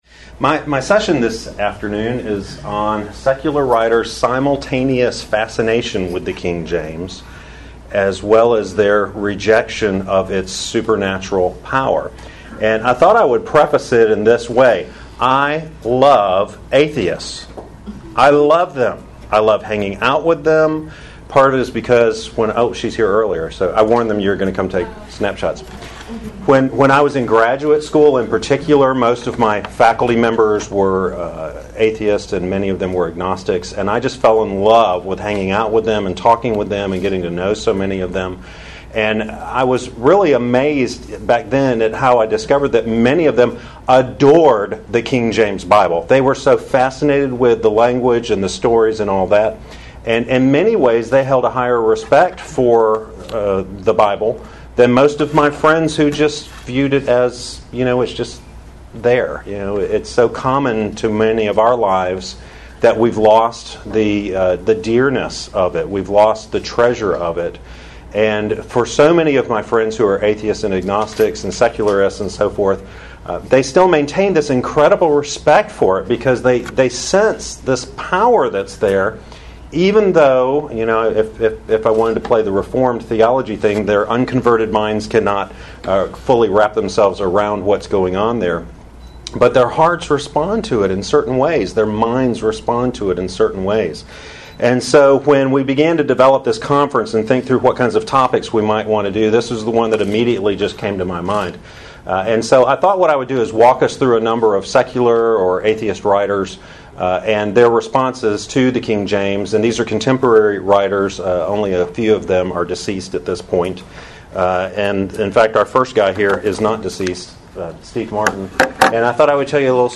Address: Give Me also This Power: Secular Writers' Simultaneous Fascination with and Denial of the Power of the KJV Recording Date